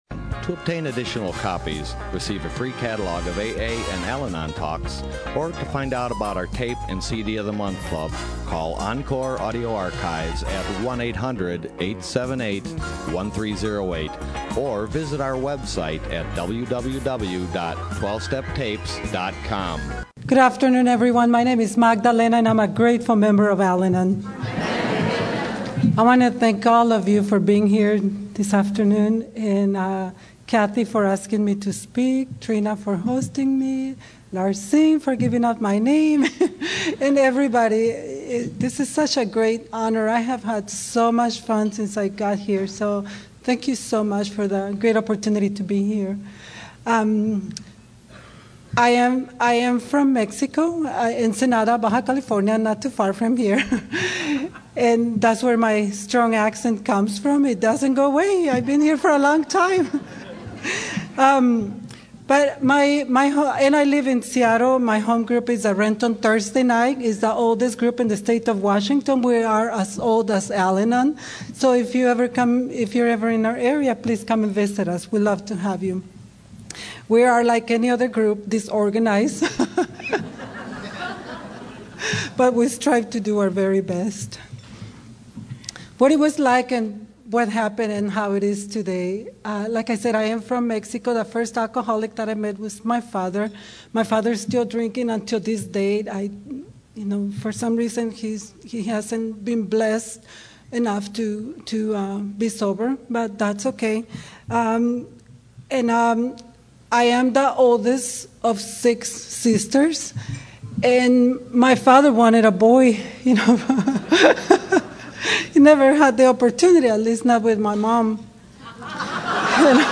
SOUTHBAY ROUNDUP 2012